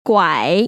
[guăi] 꽈이